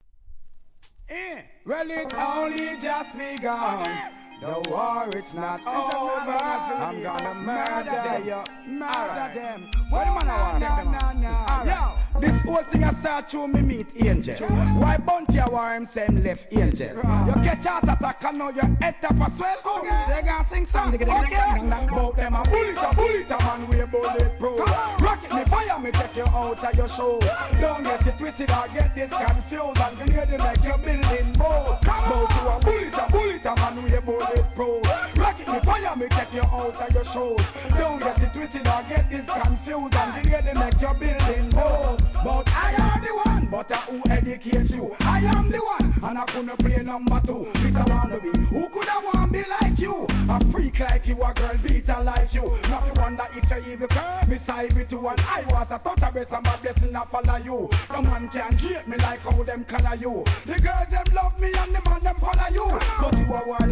REGGAE
ぶっといシンセベースを使用した